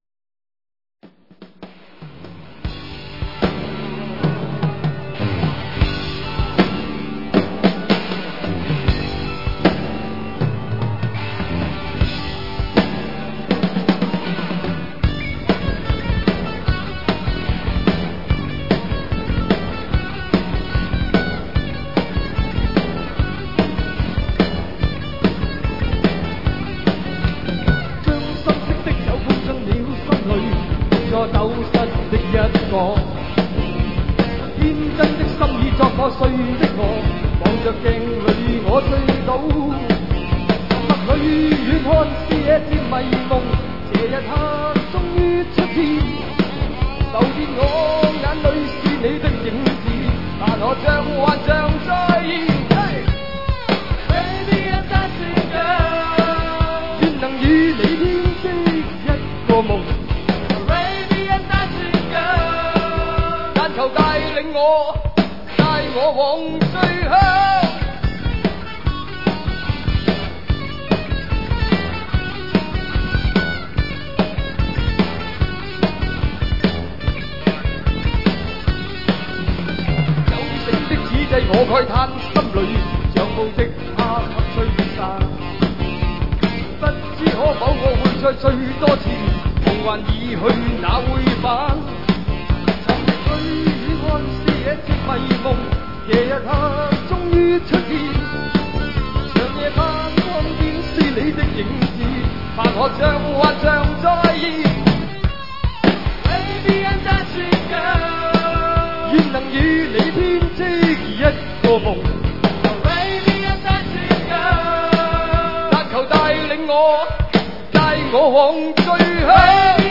1988年【北京演唱会】